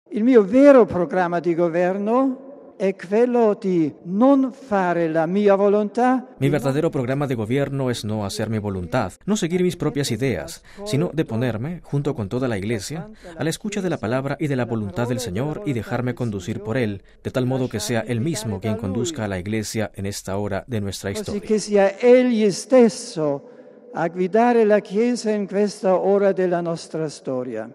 Palabras de Benedicto XVI en la primera homilía de su Pontificado.